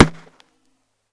Arrow_impact3.L.wav